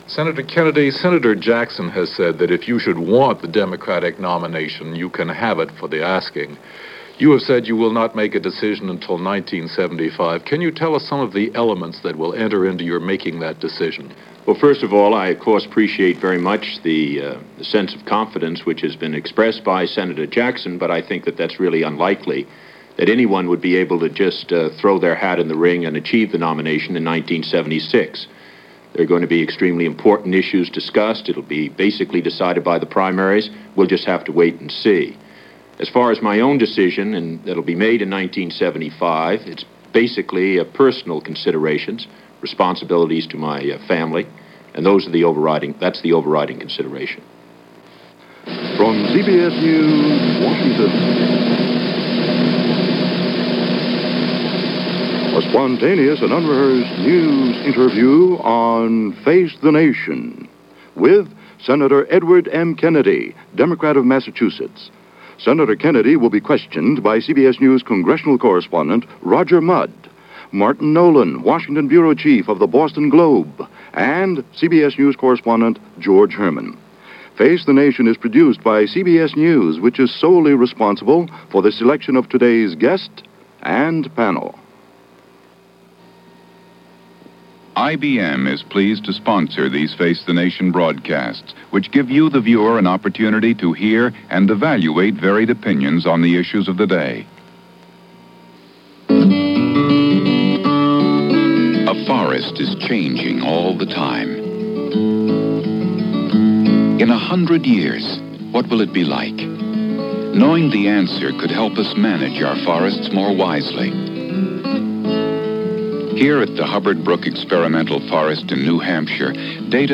February 10, 1974 - Ted Kennedy Talks About running in '76 - Chappaquiddick - Health Care - interviewed on Face The Nation - Past Daily Reference Room.